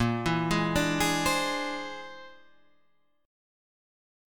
A#9b5 chord {6 5 6 7 5 8} chord